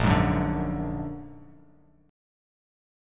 A#+7 chord